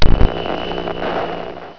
Static
STATIC.WAV